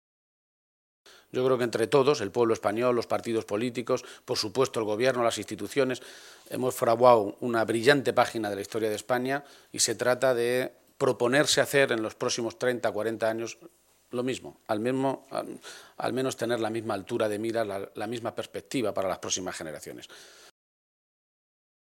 García-Page se pronunciaba de esta manera esta mañana en Toledo, a preguntas de los medios de comunicación.
Cortes de audio de la rueda de prensa